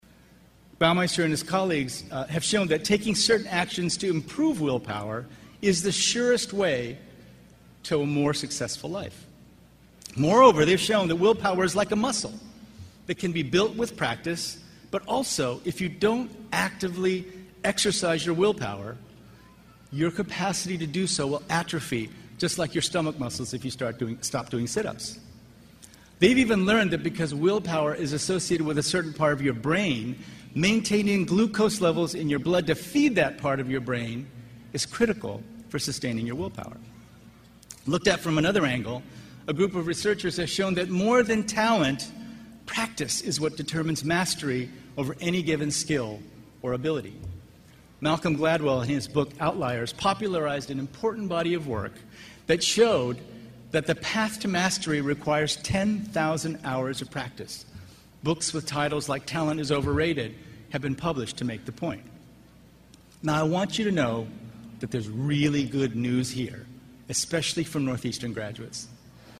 公众人物毕业演讲 第68期:金墉美国东北大学(7) 听力文件下载—在线英语听力室